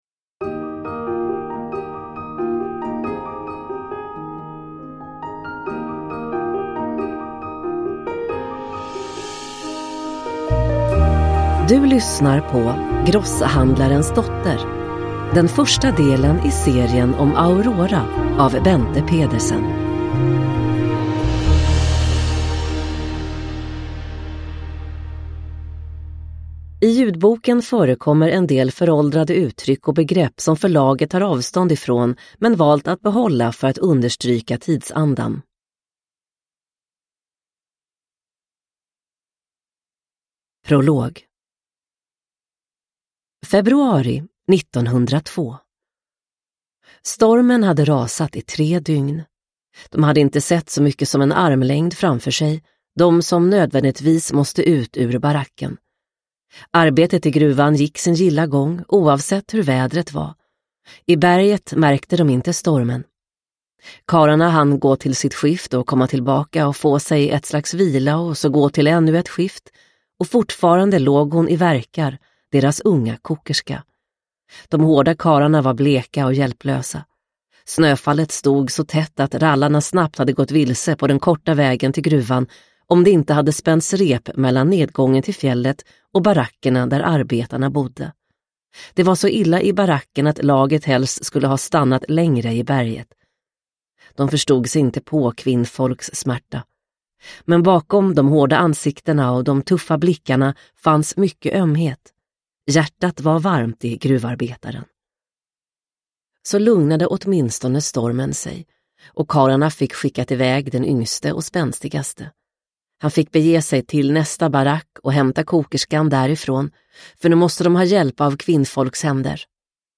Grosshandlarens dotter – Ljudbok – Laddas ner